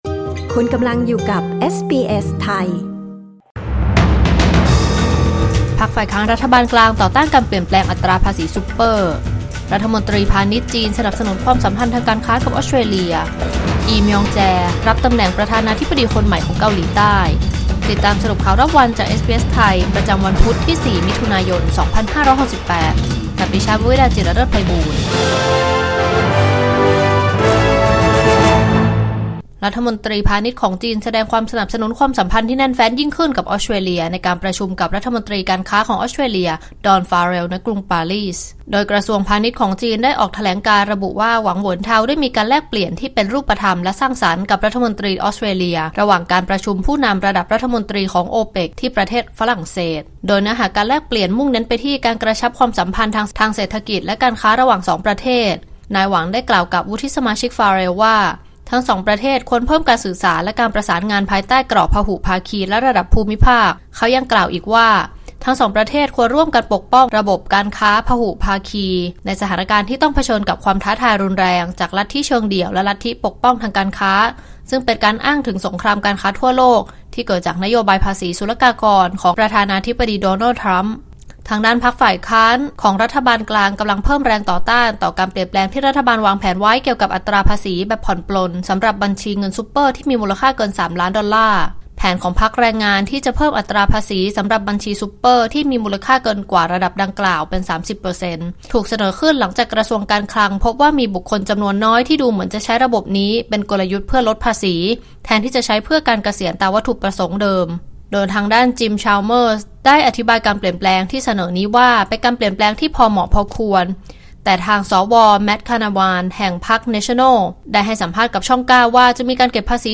สรุปข่าวรอบวัน 4 มิถุนายน 2568